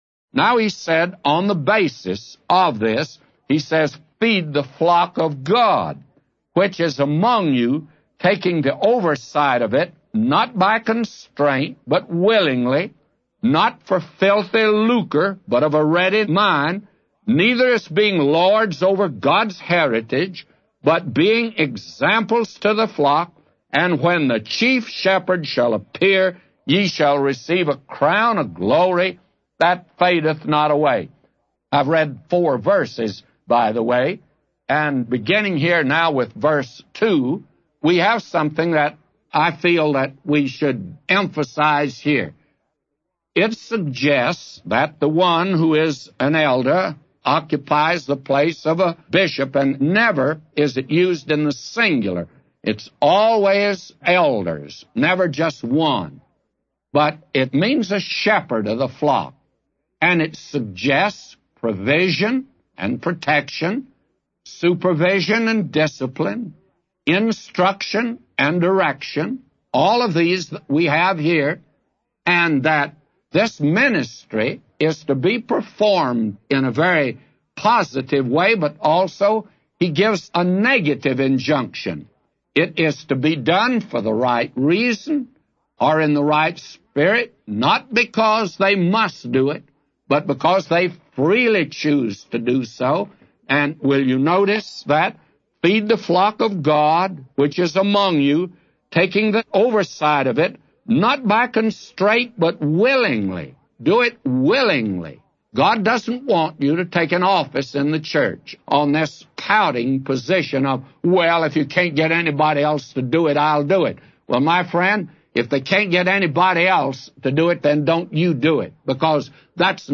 A Commentary By J Vernon MCgee For 1 Peter 5:2-999